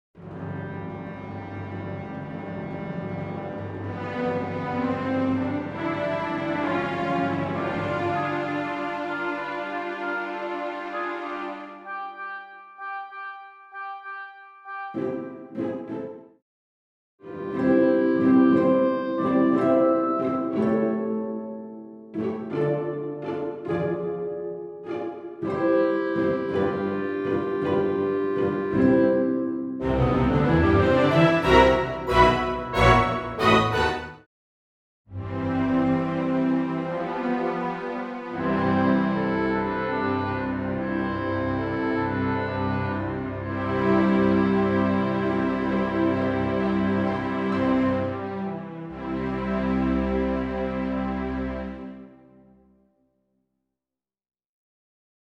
Full Orch accompaniment